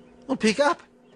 Pick up.wav